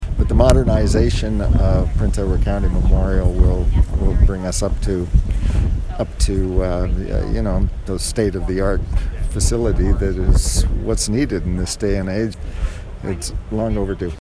Mayor Steve Ferguson was also on hand for the announcement and says making sure they have the medical facilities to deal with the aging seniors population but also the influx of visitors the municipality sees if important.